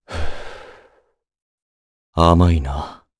Crow-Vox_Skill7_jp.wav